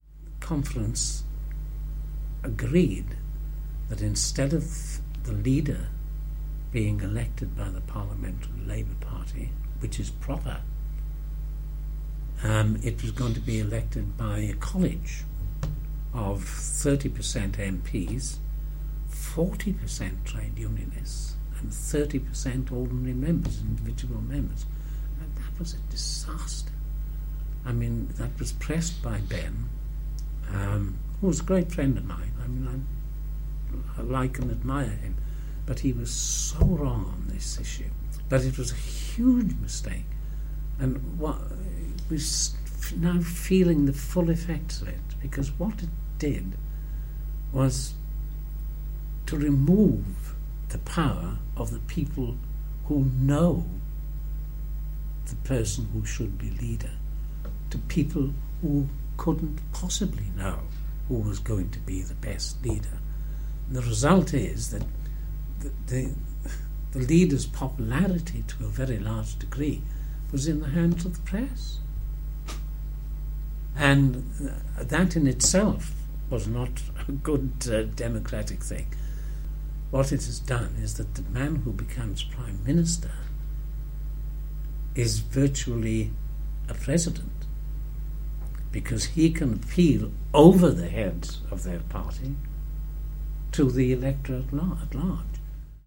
Our oral history project features many former Labour MPs’ recollections of leadership contests, and the consequences of them, particularly in the 1980s as the party grappled with life in opposition and internal divisions between left and right.
Others on the right of the party simply thought that the change was wrong, as David Stoddart, MP for Swindon (who stayed with the party during this period) describes in this clip: